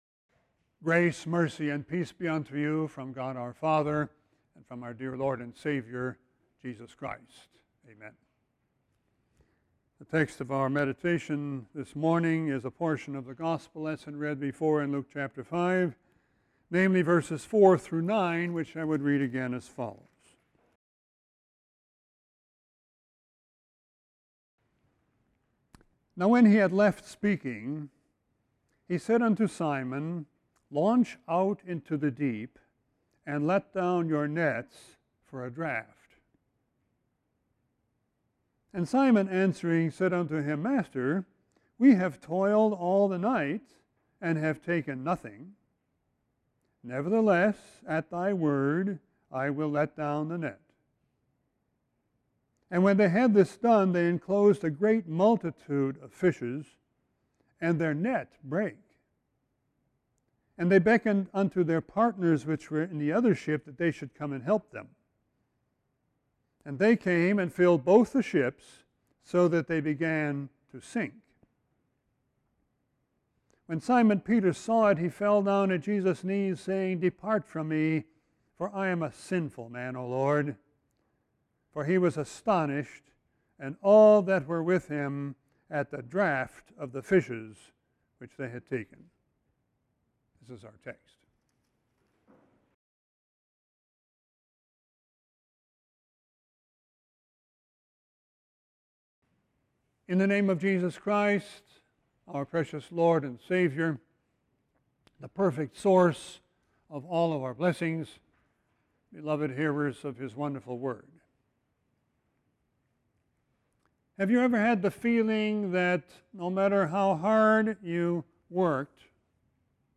Sermon 7-21-19.mp3